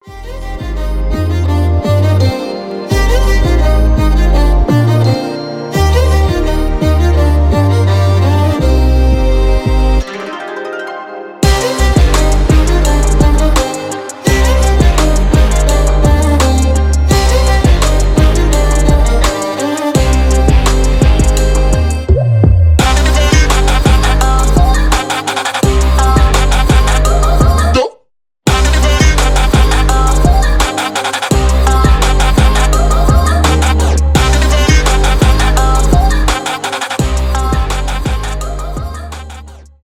инструментальные
индийские
без слов